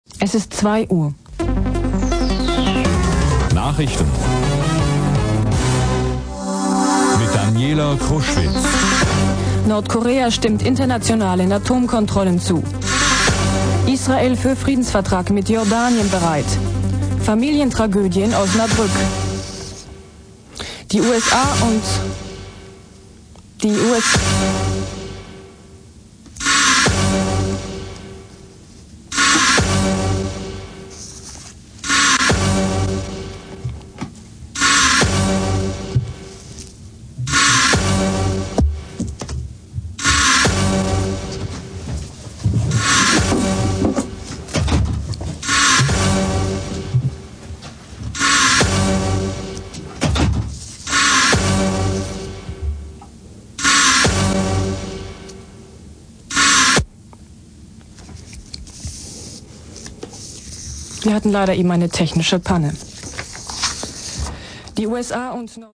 Der Trenner hakt - und die Sprecherin
gerät hörbar in Verzweiflung.